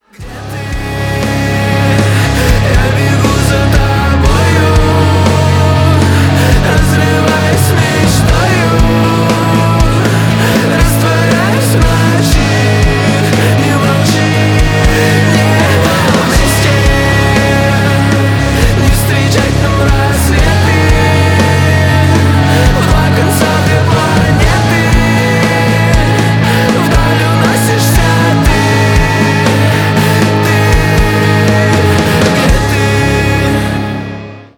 • Качество: 320, Stereo
мужской голос
громкие
жесткие
Драйвовые
электрогитара
русский рок
тяжелые